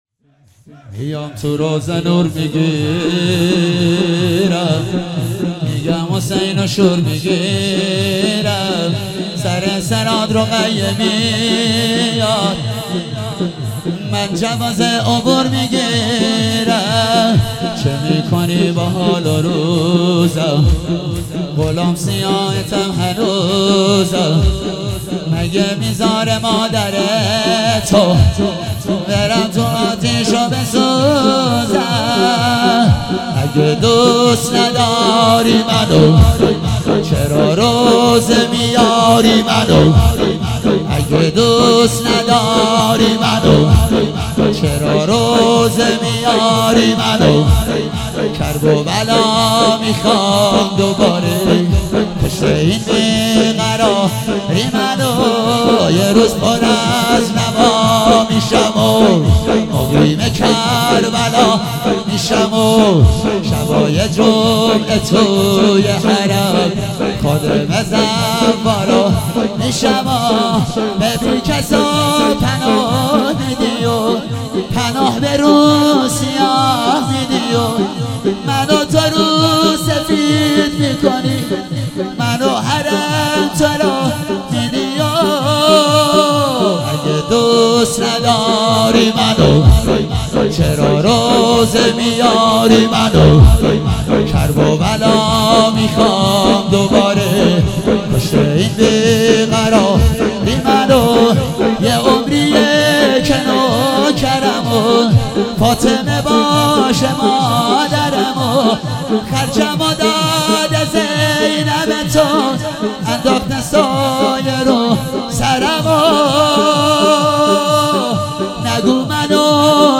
شور - میام تو روضه نور میگیرم